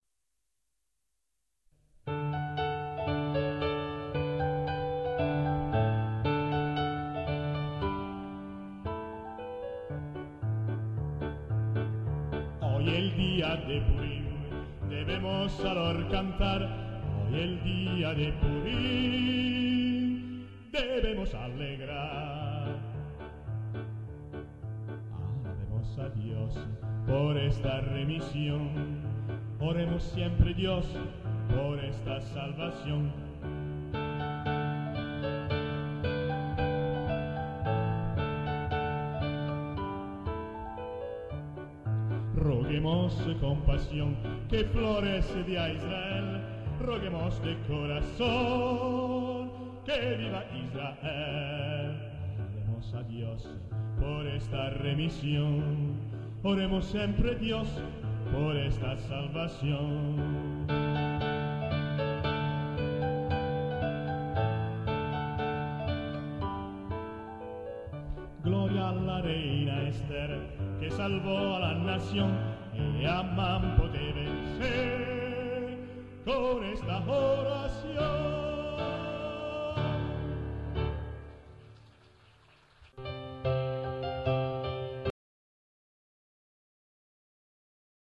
Oy el dia de Purim canto di Purim in giudeospagnolo
piano
registrazione effettuata al Teatro Paisiello di Lecce il 27 gennaio 2004